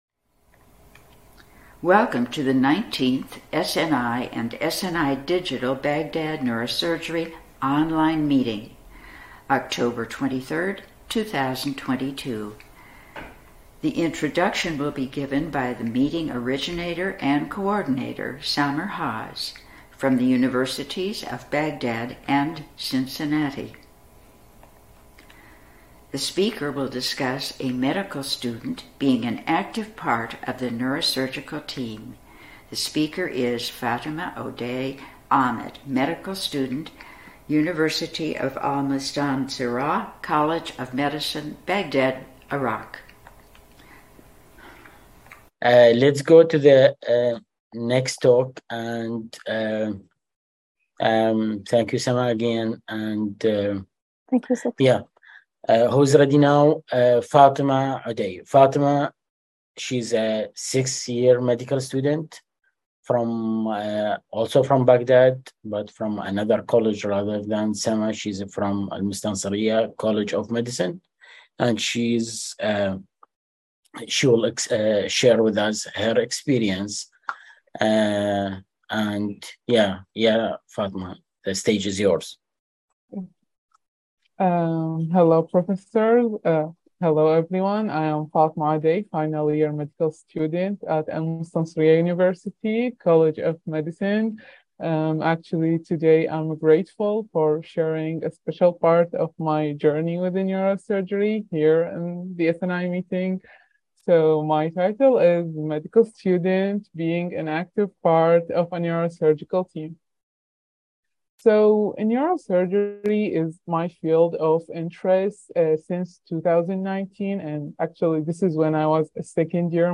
Hoz Mentorship; YNSS Education; Lecture and Discussion